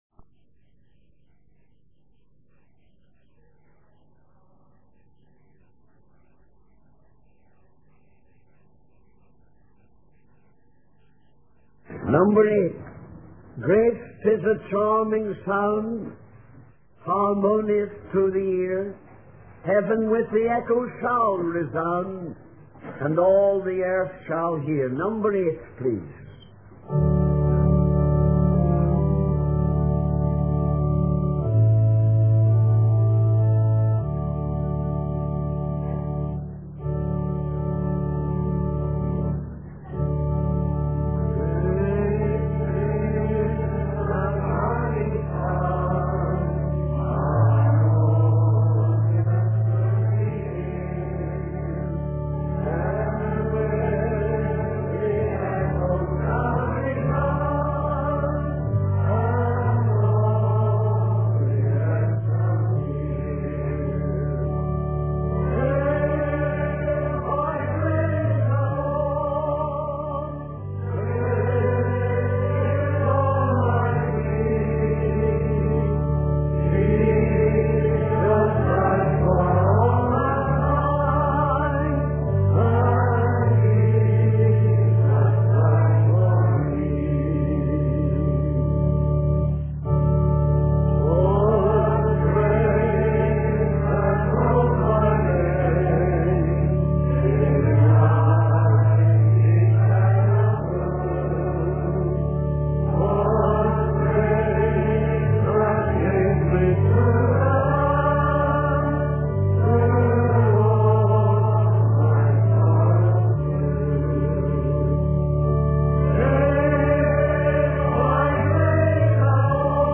In this sermon, the speaker discusses the book of Revelation and its various aspects. He emphasizes the importance of understanding the title of the book, which is the revelation of Jesus Christ given by God.